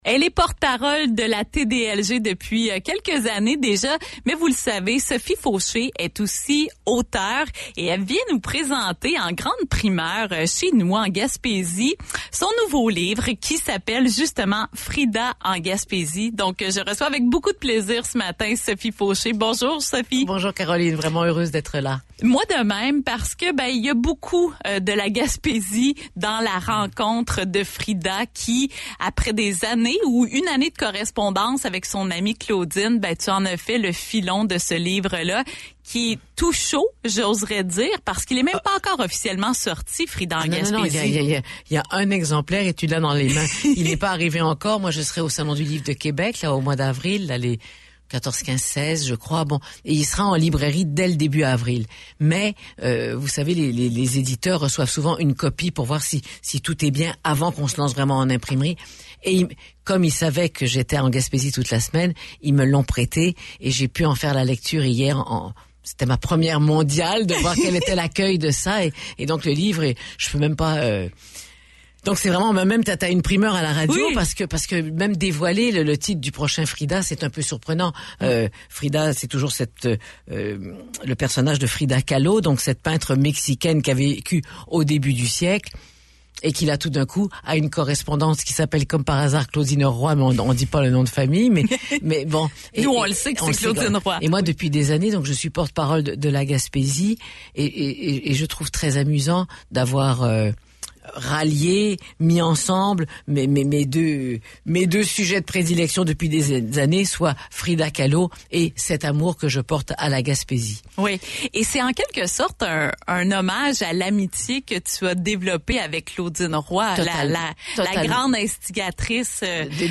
À l’occasion de sa participation à la 20e édition de la TDLG, l’auteure et comédienne Sophie Faucher a présenté en primeur son nouveau livre « Frida en Gaspésie » qui sortira officiellement en avril prochain.